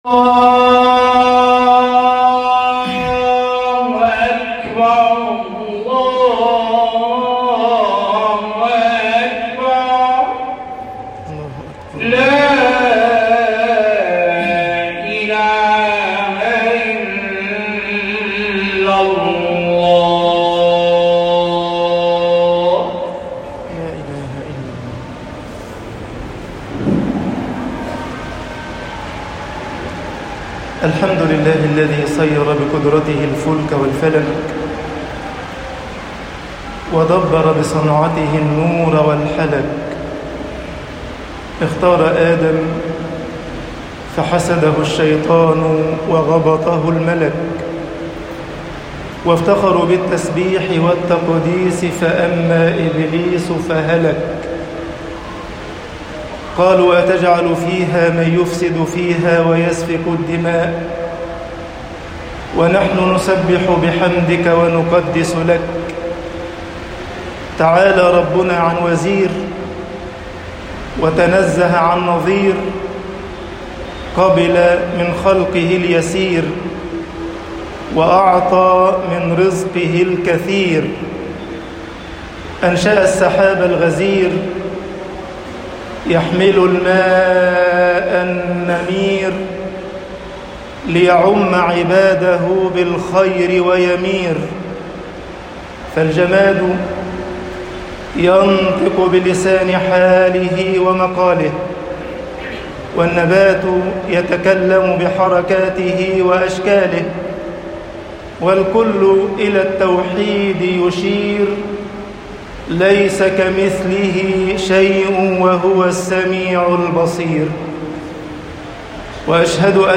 خطب الجمعة - مصر مُقَارَنَةٌ مُوجِعَةٌ